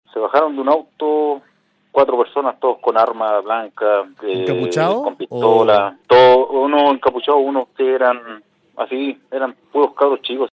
Él conversó con nosotros y detalló este violento robo.